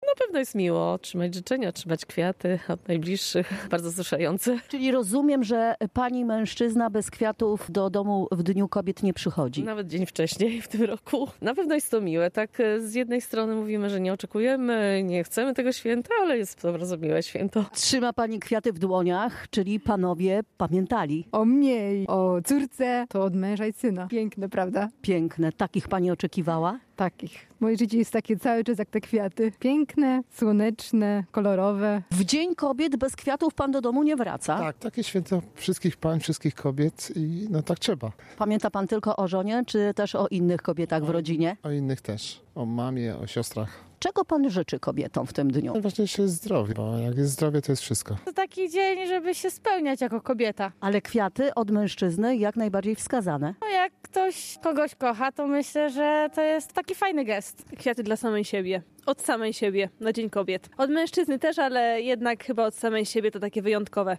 Wiadomości • Mieszkanki Rzeszowa zapytaliśmy, czy w tym dniu wciąż liczą na miłe gesty.